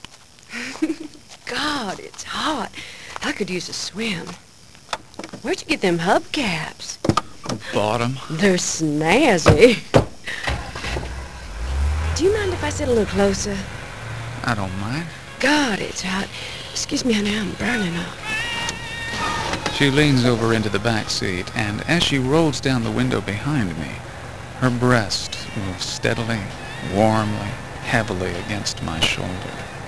and venuesfor his radio theatre adaptation of clyde edgerton's. clyde edgerton's.
With a cast of some of Chicago's finest actors, including: